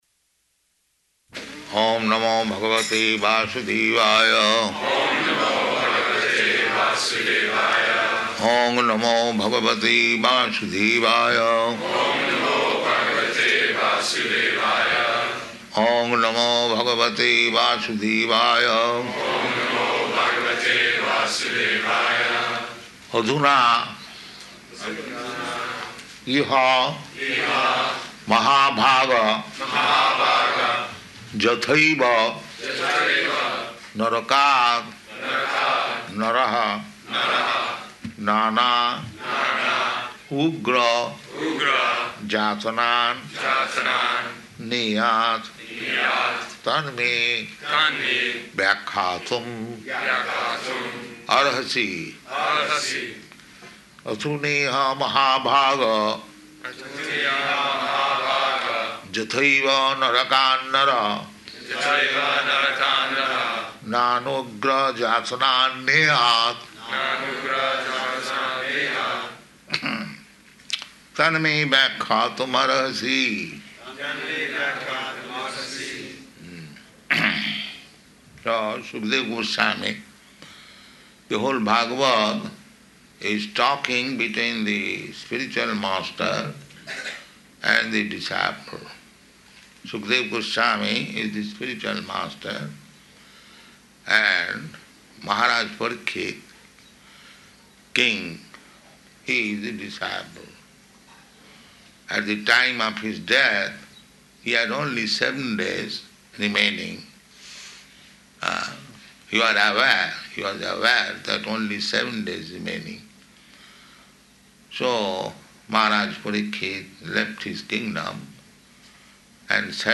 Śrīmad-Bhāgavatam 6.1.6-7 --:-- --:-- Type: Srimad-Bhagavatam Dated: June 8th 1975 Location: Honolulu Audio file: 750608SB.HON.mp3 Prabhupāda: Oṁ namo bhagavate vāsudevāya.
[chants with devotees responding] [chants verse, word for word, with devotees responding] adhuneha mahā-bhāga yathaiva narakān naraḥ nānogra-yātanān neyāt tan me vyākhyātum arhasi [ SB 6.1.6 ] So Śukadeva Gosvāmī... The whole Bhāgavata is talking between the spiritual master and the disciple.